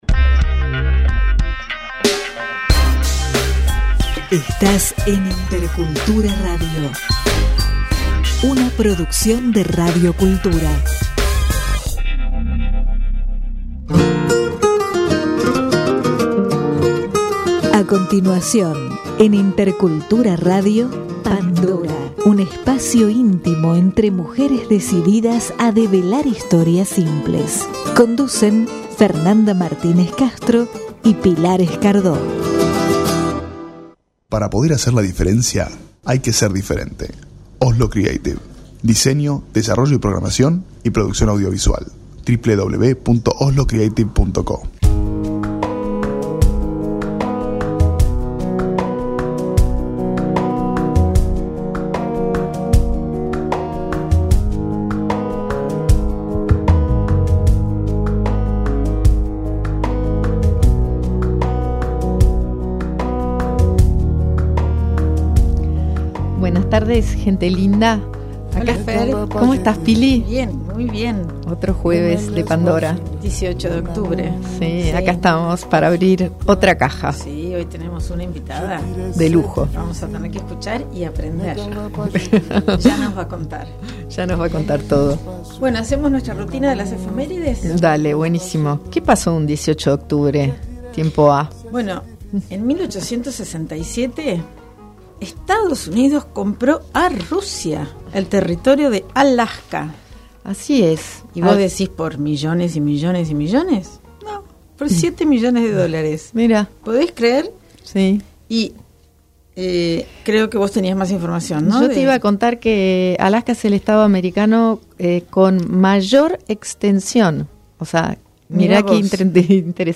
Para escuchar la entrevista completa podés hacer click al pié de la nota.